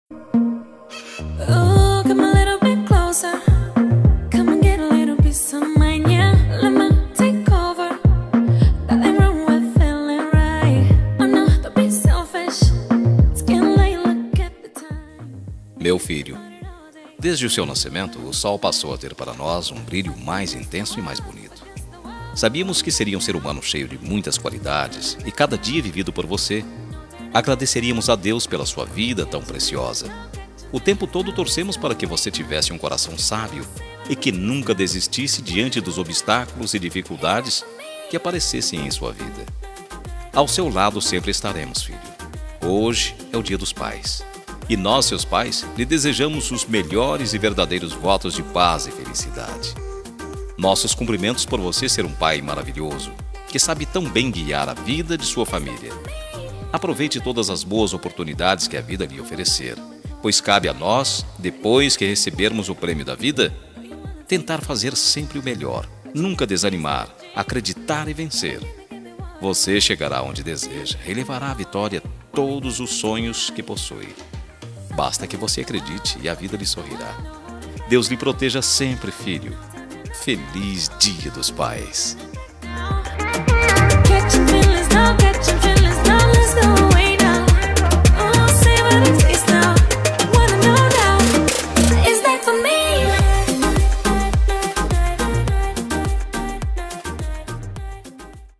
Voz Masculina